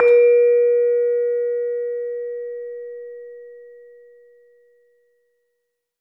LAMEL A#3 -L.wav